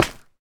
Minecraft Version Minecraft Version 25w18a Latest Release | Latest Snapshot 25w18a / assets / minecraft / sounds / block / froglight / step1.ogg Compare With Compare With Latest Release | Latest Snapshot
step1.ogg